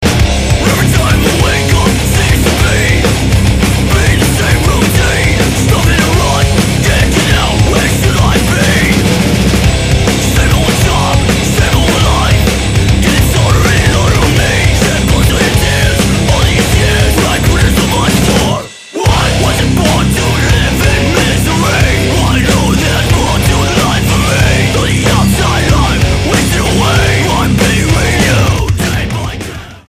STYLE: Hard Music